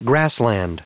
Prononciation du mot grassland en anglais (fichier audio)
Prononciation du mot : grassland